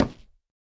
Minecraft Version Minecraft Version 1.21.5 Latest Release | Latest Snapshot 1.21.5 / assets / minecraft / sounds / block / nether_wood / break4.ogg Compare With Compare With Latest Release | Latest Snapshot
break4.ogg